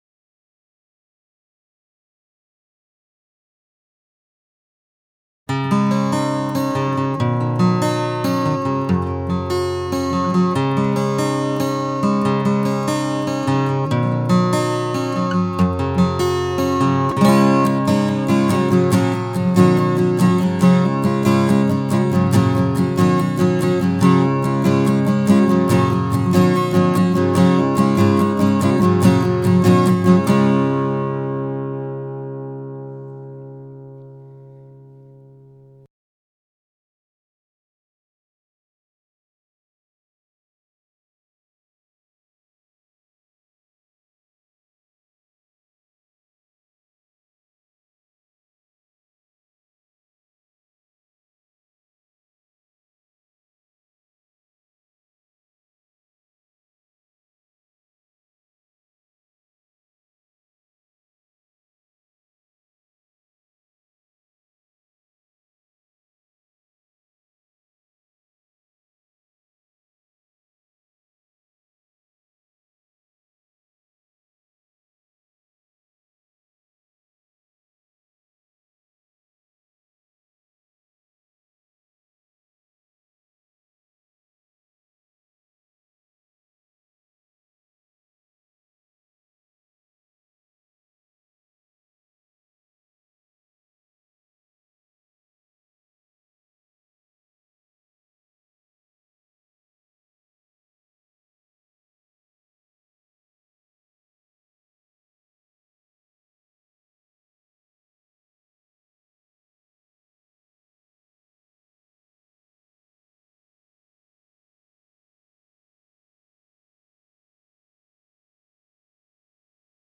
Schoeps Vintage CMT 341 Microphone: CMT 3 body with MK41 hypercardioid capsule
Collings Acoustic |
SIGNAL CHAIN: Schoeps CMT 341 / Presonus ADL 600 / Rosetta 200 / Logic.